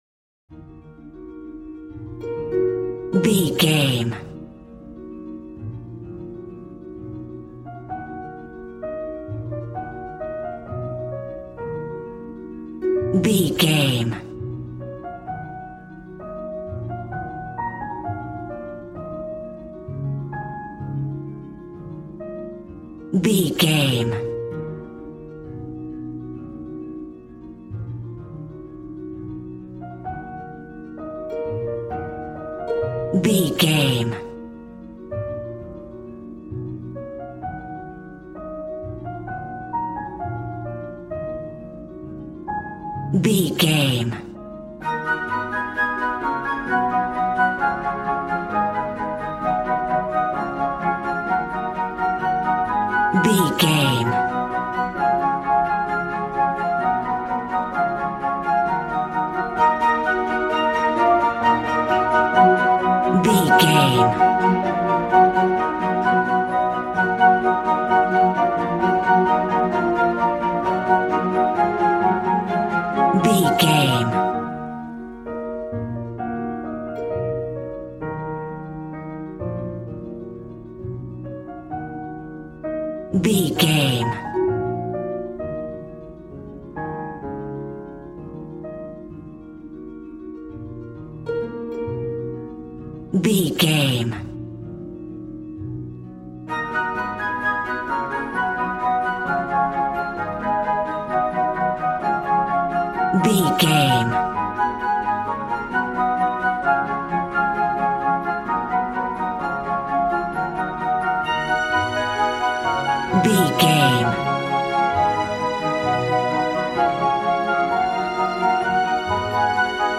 Regal and romantic, a classy piece of classical music.
Ionian/Major
G♭
regal
strings
violin